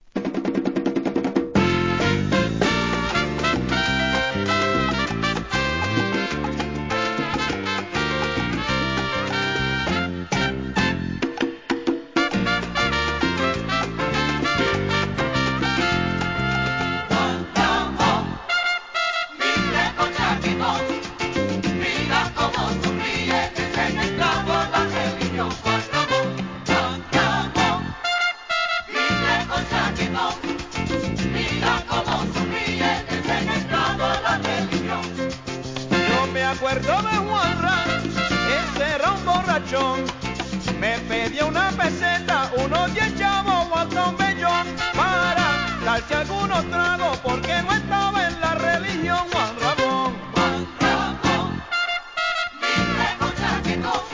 店舗 ただいま品切れ中です お気に入りに追加 1981年、サルサ・ラテンALBUM!!